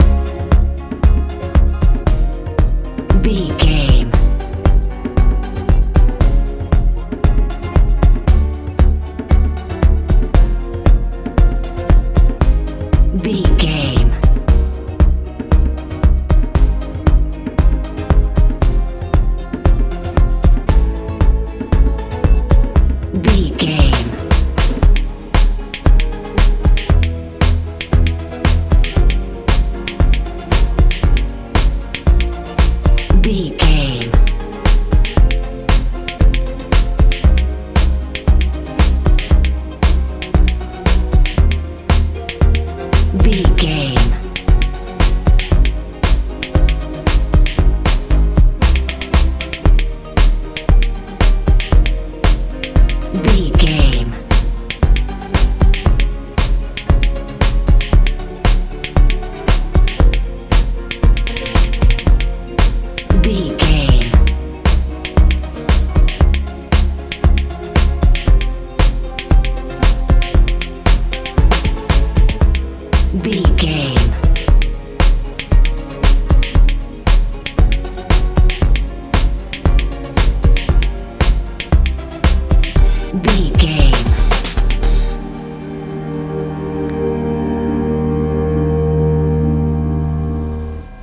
euro dance feel
Ionian/Major
B♭
hopeful
hypnotic
bouncy
synthesiser
electric guitar
piano
bass guitar
drums
80s
90s
strange